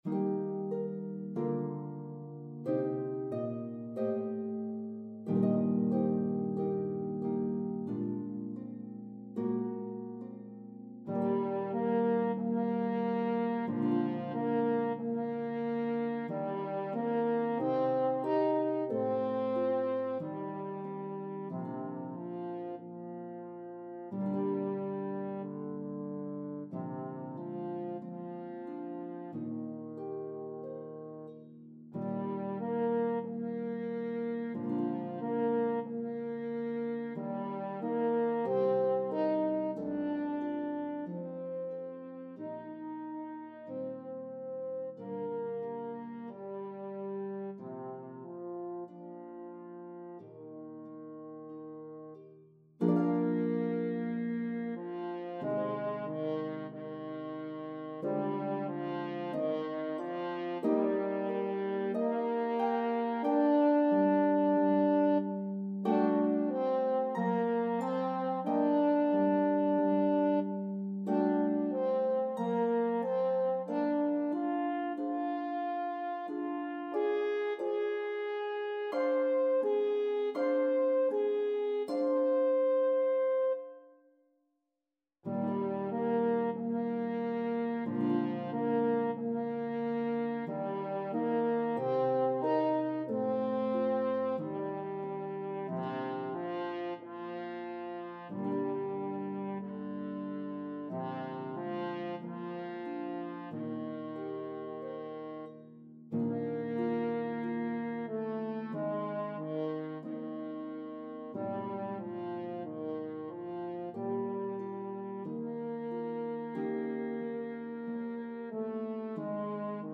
The elegant, famous melody will enchant your audiences!
Harp and French Horn version